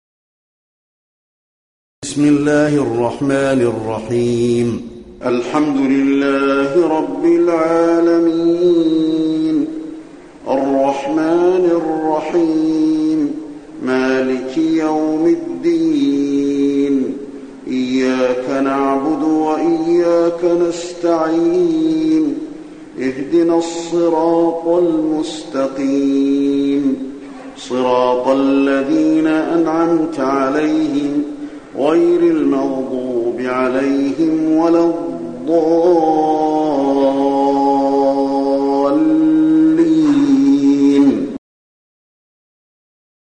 المكان: المسجد النبوي الفاتحة